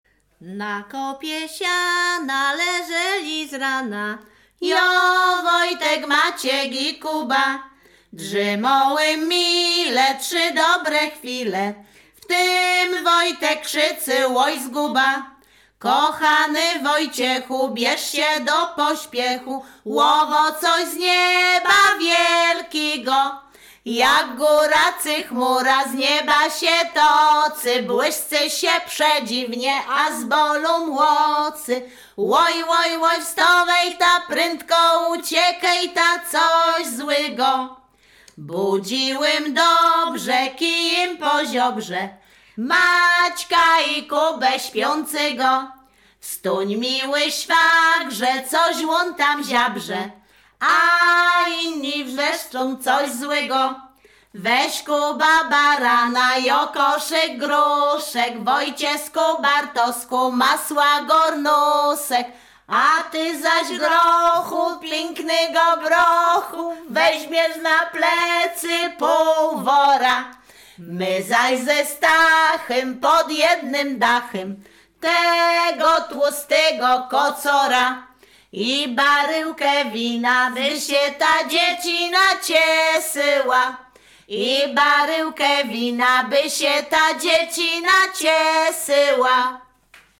Śpiewaczki z Chojnego
województwo łódzkie, powiat sieradzki, gmina Sieradz, wieś Chojne
Pastorałka
Array kolędy kolędowanie zima bożonarodzeniowe pastorałki